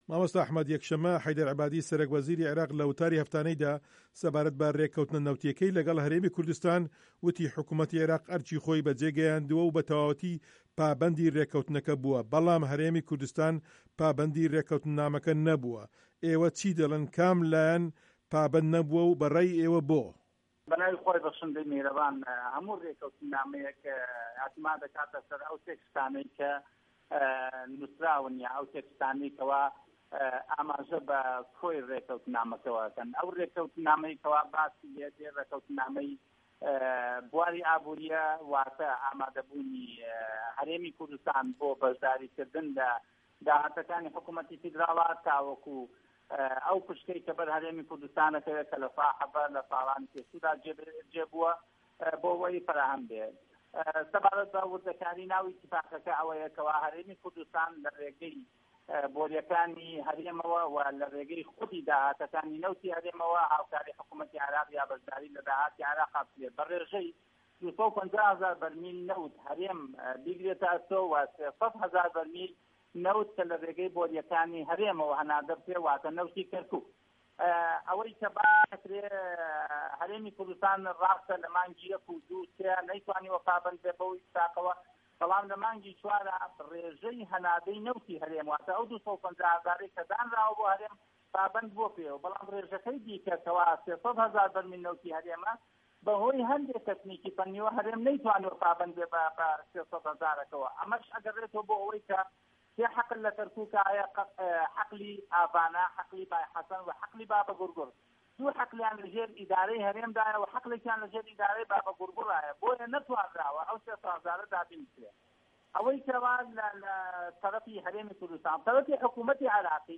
هه‌رێمه‌ کوردیـیه‌کان - گفتوگۆکان
پەرلەمانتار ئەحمەد حاجی ڕەشید، ئەندامی لیژنەی دارایی لە پەرلەمانی عێراق لەسەر لیستی کۆمەڵی ئیسلامی کوردسـتان لە وتووێژێـکی بۆ بەشی کوردی دەنگی ئەمەریکا لەم بارەیەوە دەدوێت.